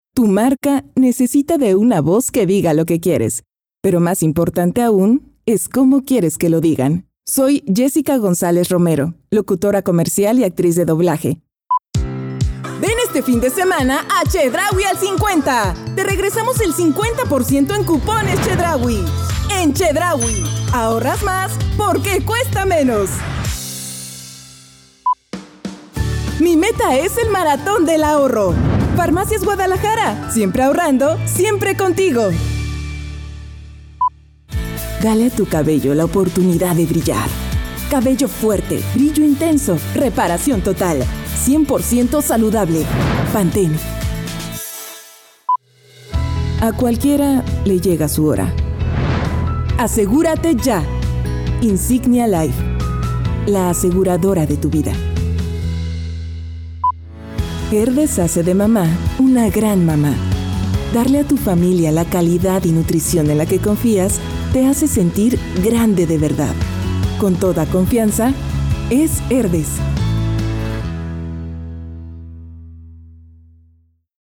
西班牙语女声
低沉|激情激昂|大气浑厚磁性|沉稳|娓娓道来|科技感|积极向上|时尚活力|神秘性感|调性走心|亲切甜美|素人